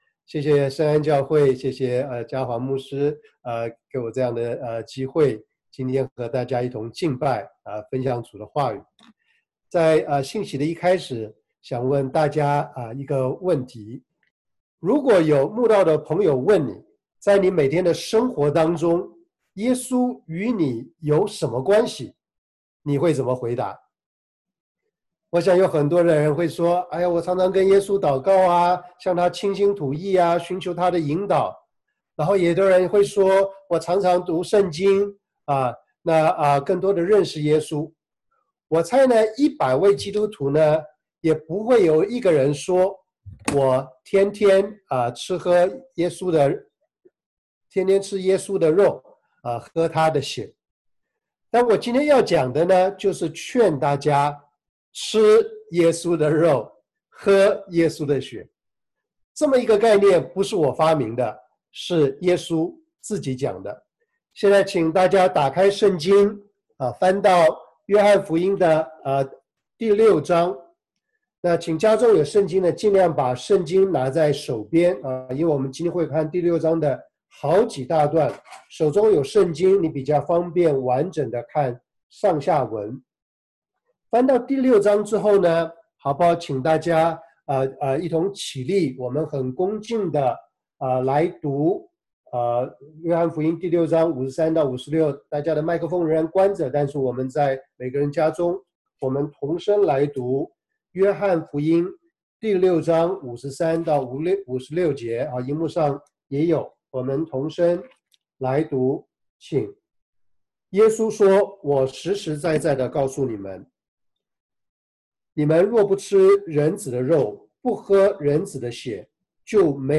John 6 Service Type: Sunday Service 吃耶穌的肉，喝耶穌的血 約翰福音 6:53-56 猶太人的反應